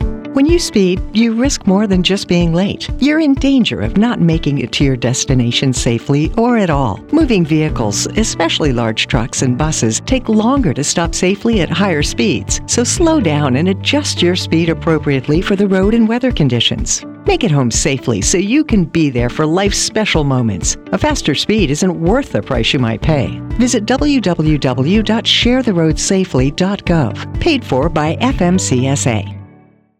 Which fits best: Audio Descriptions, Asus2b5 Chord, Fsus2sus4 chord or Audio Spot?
Audio Spot